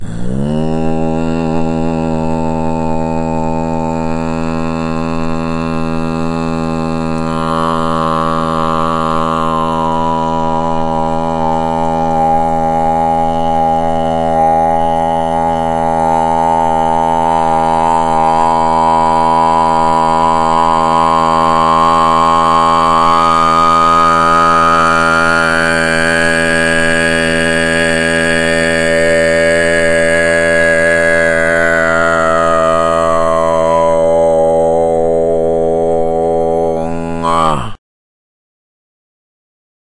喉部歌唱 " 低矮的藏语吟唱
描述：低而短
标签： 佛教 khumi 麦克风 蒙古语 泛音 实践 礼拜 礼拜 录音 宗教 唱歌 密宗 密宗 西藏 图瓦人 图瓦 umzie 低音 人声 声音 愤怒瑜伽